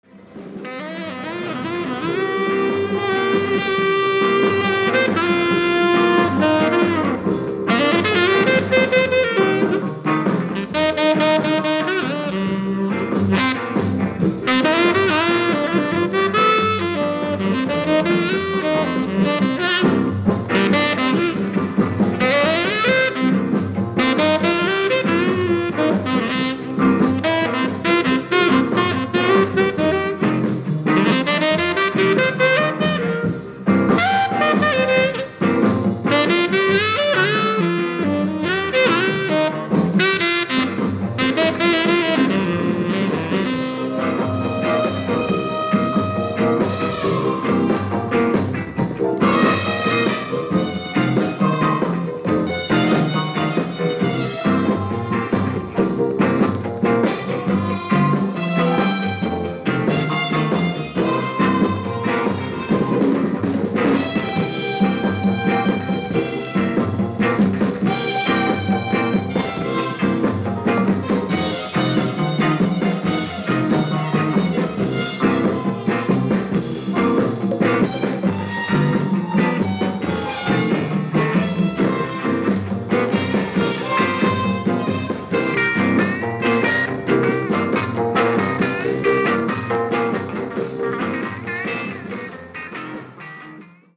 Soundtrackです。